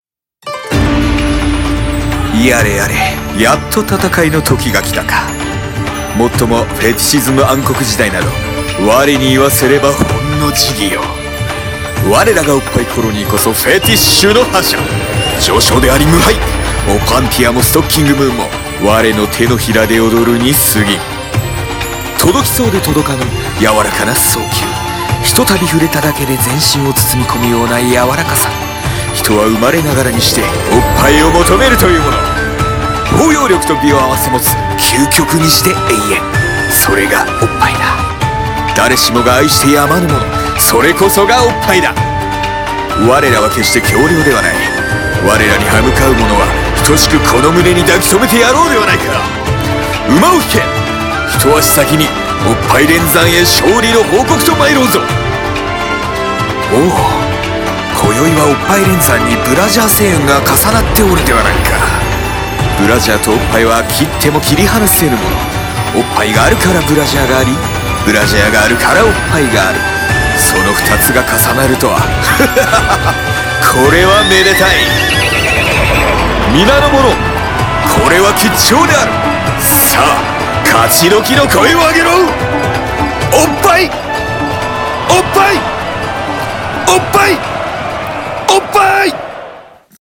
オッパイコロニー大戦開戦前夜の哄笑(一人声劇